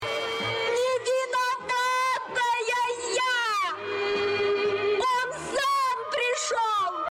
На этой странице собрана коллекция звуков, сопровождающих мелкие проблемы и досадные недоразумения.